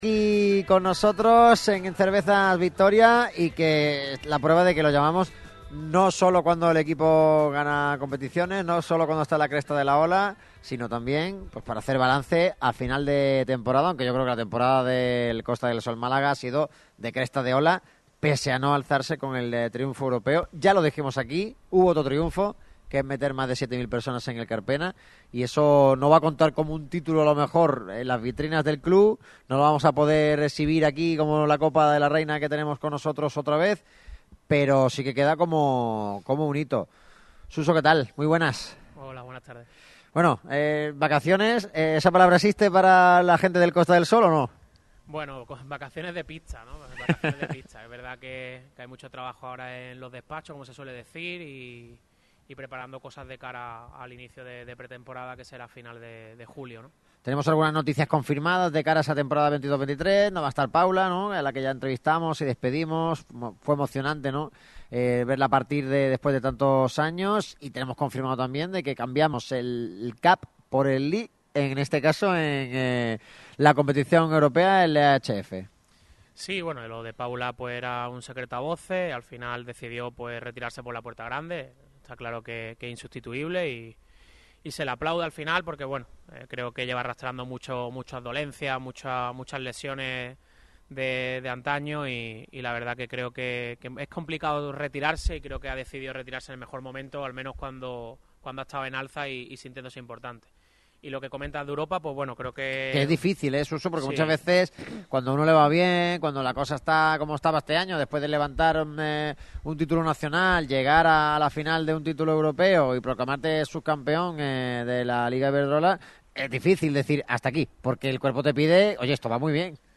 quien atendió a los micrófonos de Radio MARCA Málaga en el programa especial desde Cervezas Victoria.